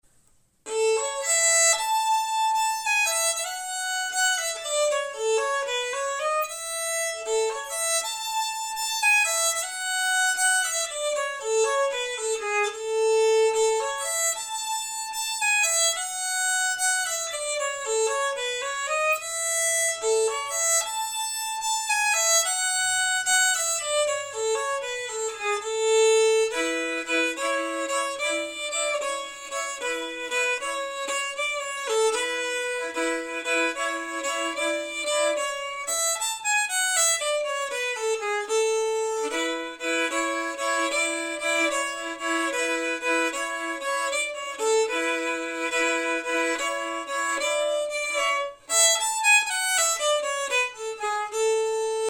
Key: A
Form: Haaling*
R: jig
M: 6/8